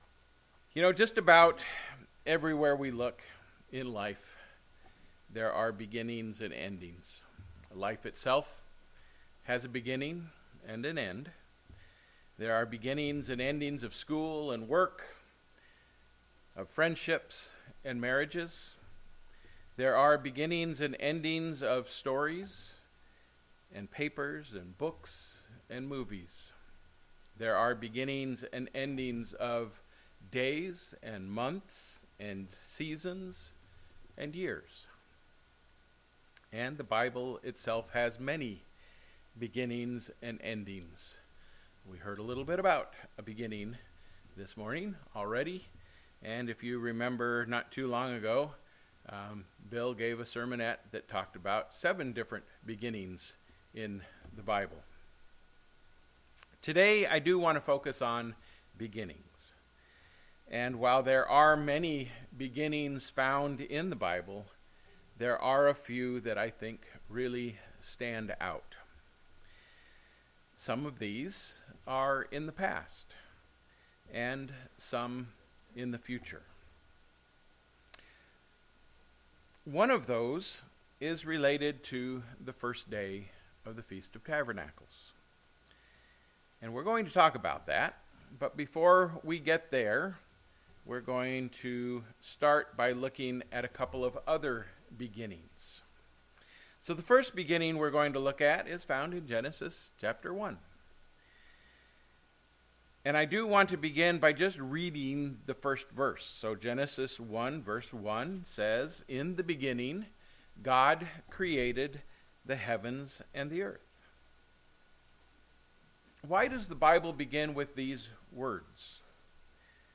October 22, 2022 Freeland, Michigan.